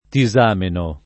[ ti @# meno ]